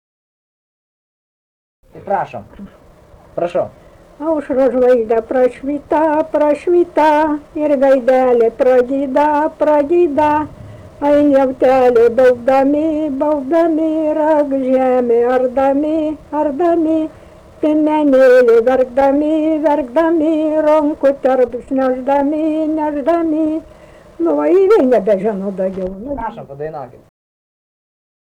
smulkieji žanrai
vokalinis